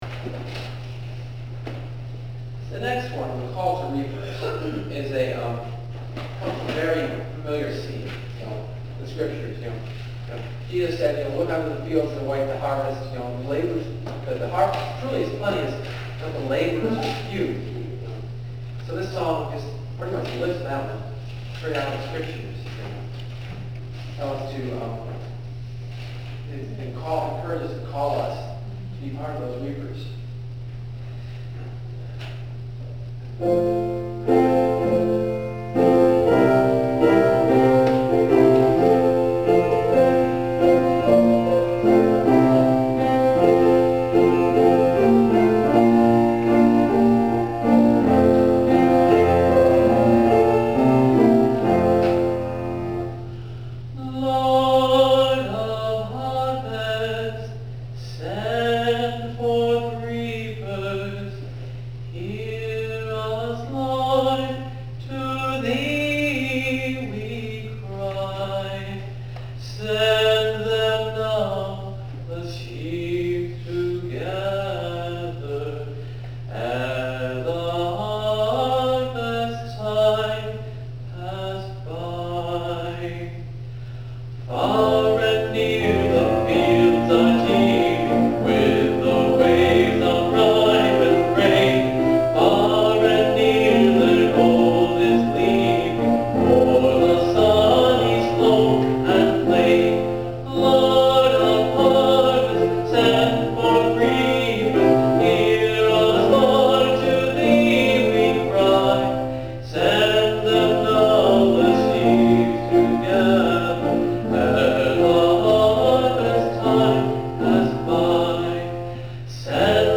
(Part 10 of the series on my only solo concert to date: A Concert of Sacred Metaphors, 2006).
I used the A Capella echo for the beginning, and the ending then, as I did in the concert, but varied from each other.